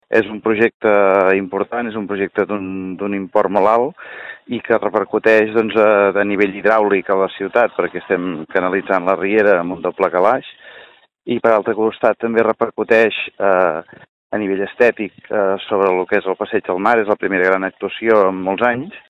Té un pressupost de gairebé 1,2 milions d’euros, una inversió que es divideix en dos anys, amb càrrec als pressupostos de 2017 i 2018. Ho explica el mateix Josep Saballs: